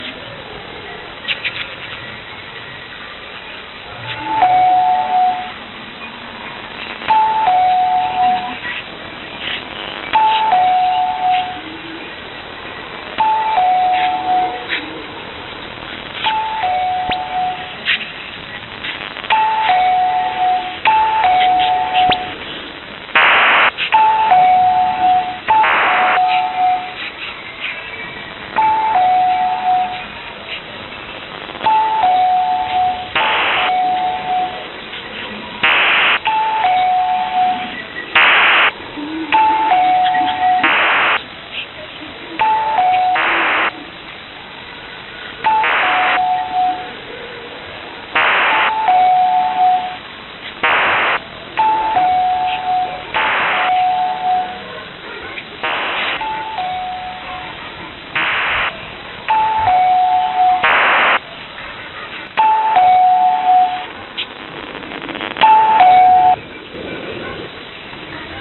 Doorbell + PSK-8, 2021-02-25, 1050 UTC, 11542 kHz